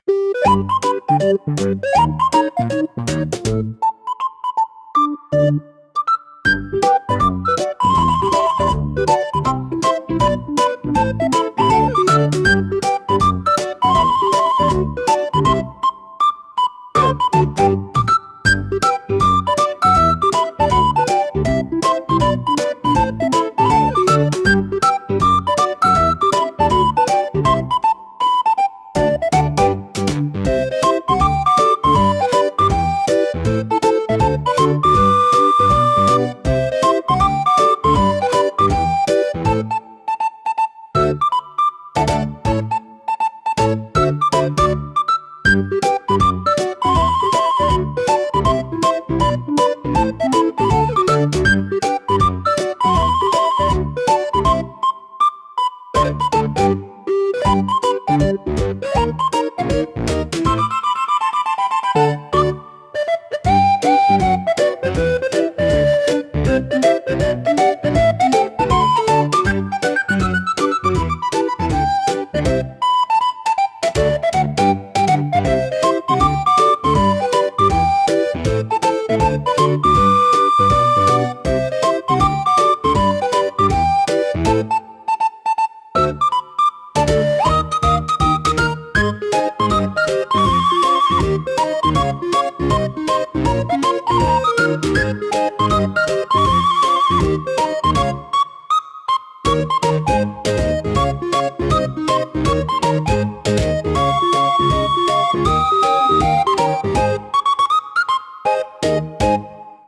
柔らかく優しい音色が心を落ち着かせ、自然でゆったりとした雰囲気を醸し出します。
BGMセミオーダーシステム ほのぼのは、リコーダーを主体にした和やかで穏やかな楽曲です。
ほのぼの , ムービー , リコーダー , 日常 , 春 , 昼 , 穏やか , 笛 , 静か